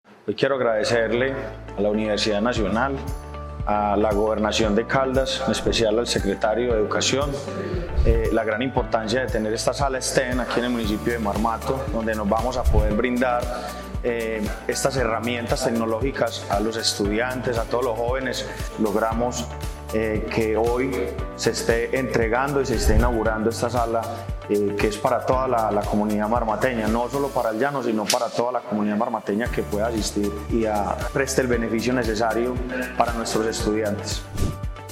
Alcalde de Marmato, Carlos Alberto Cortés.
Alcalde-de-Marmato-Carlos-Alberto-Cortes-1.mp3